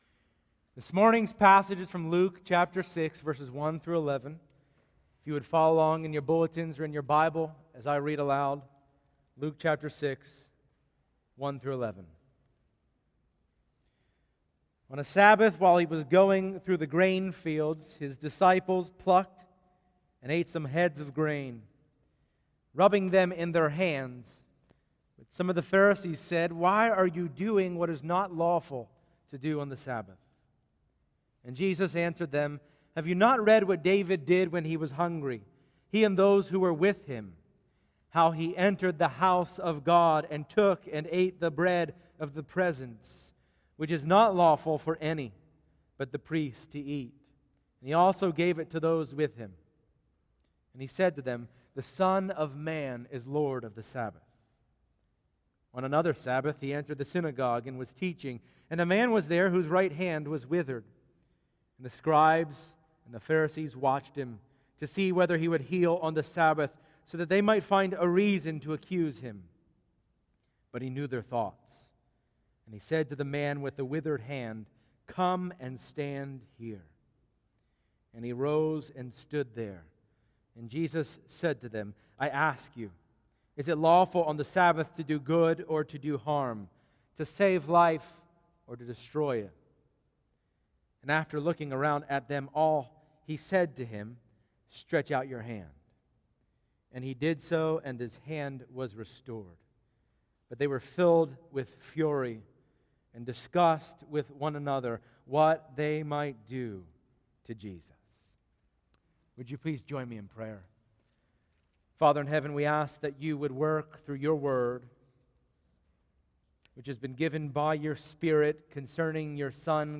Passage: Luke 6:1-11 Service Type: Sunday Morning